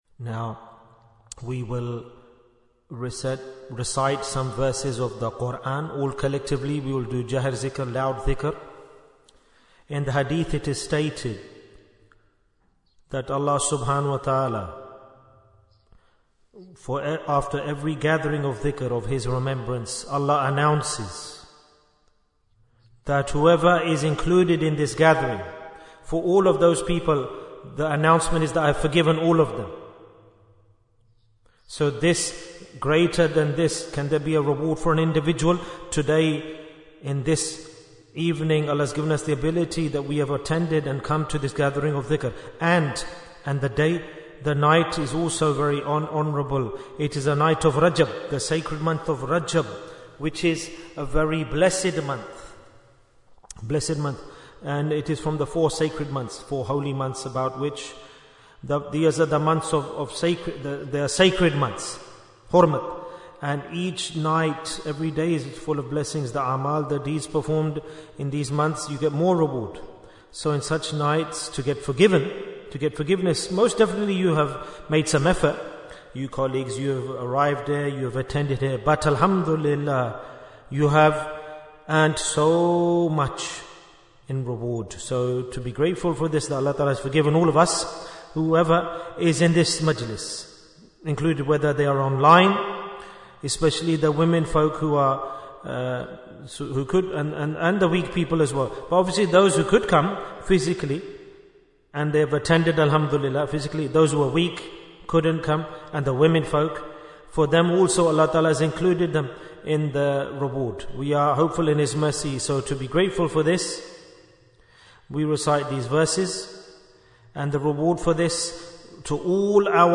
Talk before Dhikr 162 minutes20th January, 2025